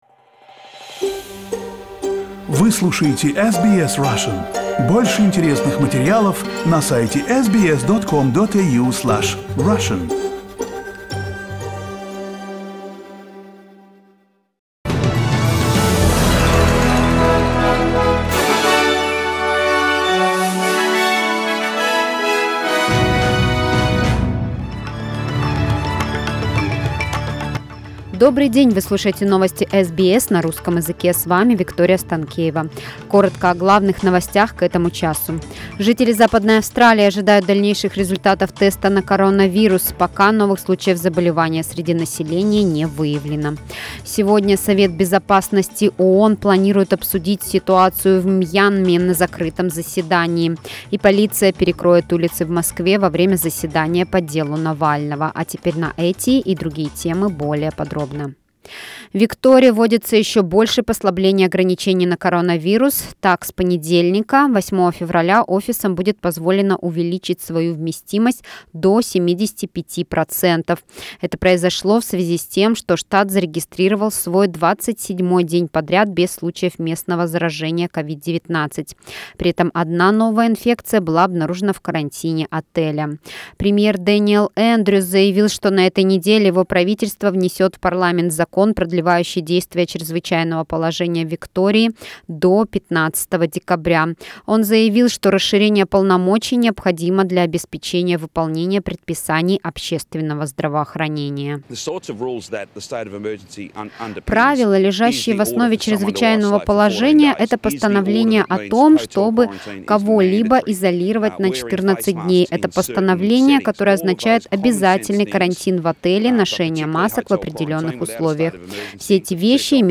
News bulletin February 2nd